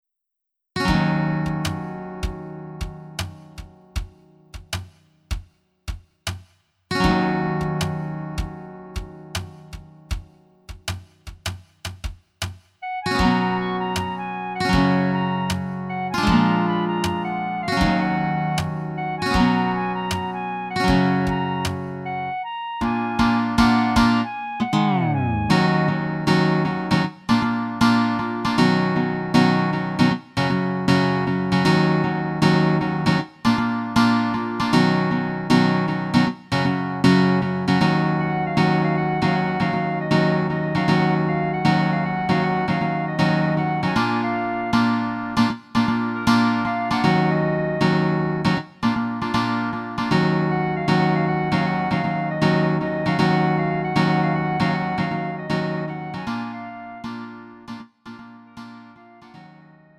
음정 -1키 2:51
장르 가요 구분 Lite MR